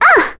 Requina-Vox_Damage_01.wav